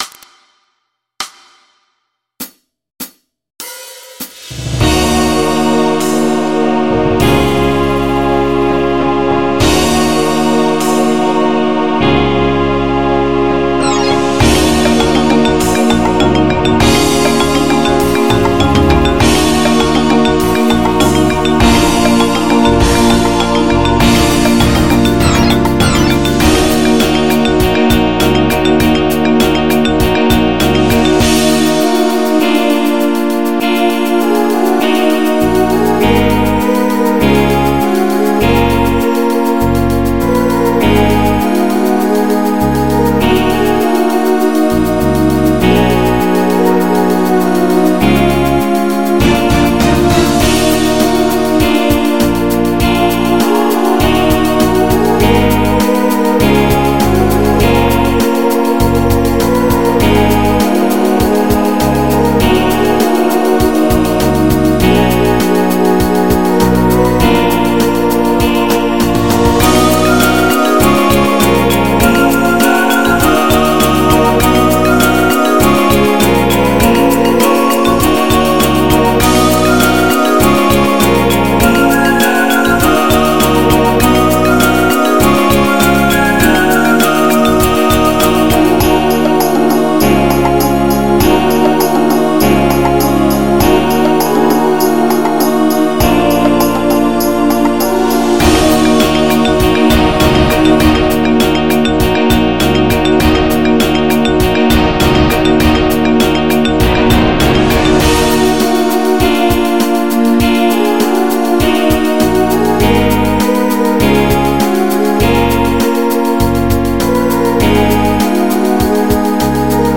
MIDI 50.59 KB MP3 (Converted)